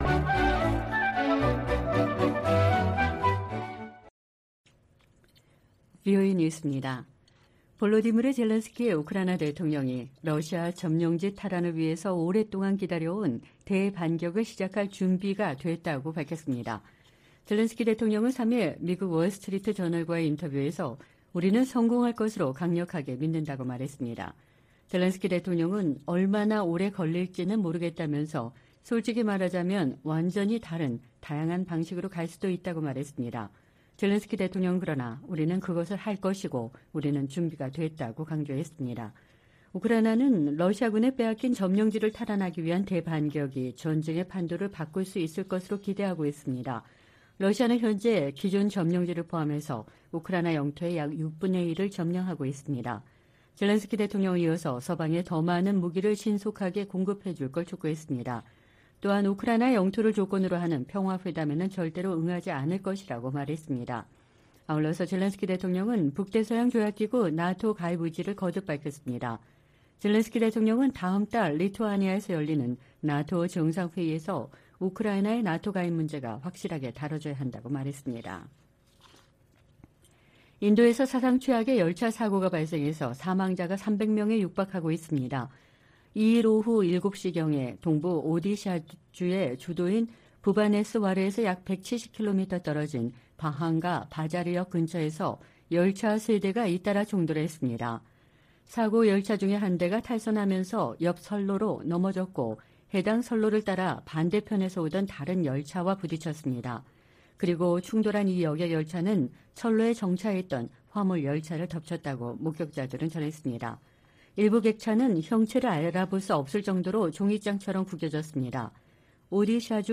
VOA 한국어 방송의 토요일 오후 프로그램 2부입니다.